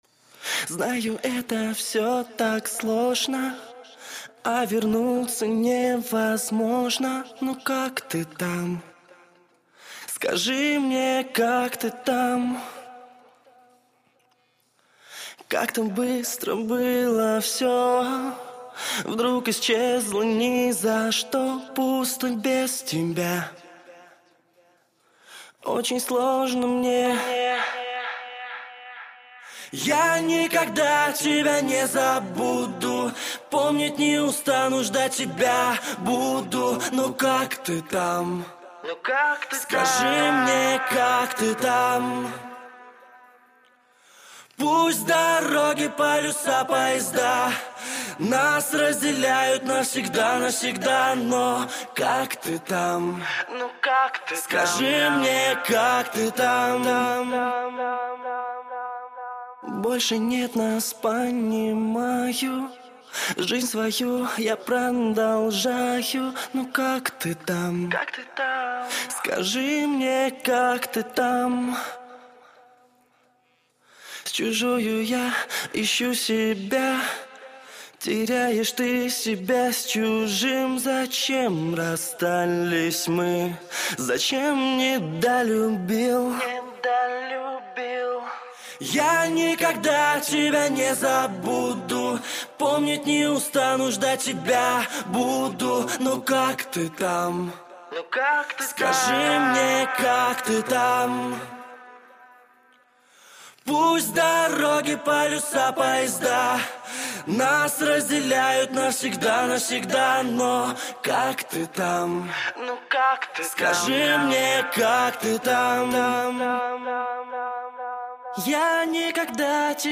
Главная » Файлы » Акапеллы » Скачать Русские акапеллы